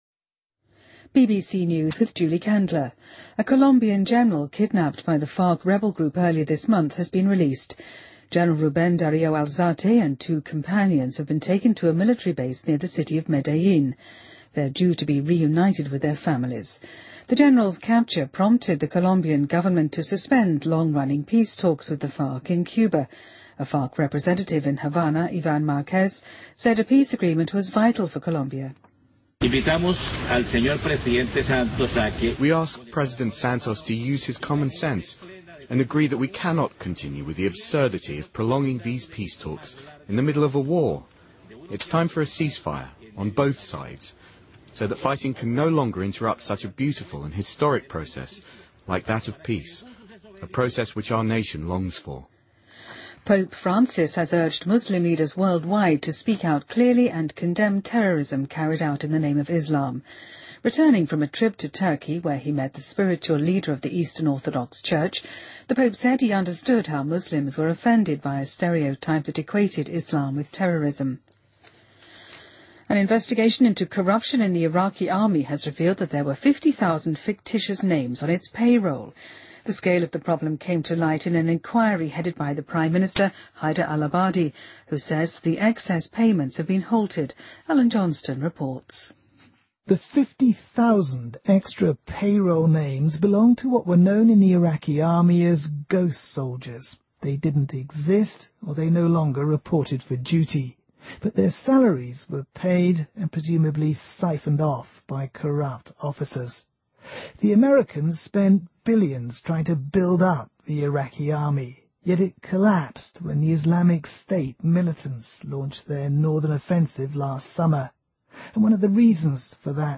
BBC news,法语国家组织选举首位女性担当领导人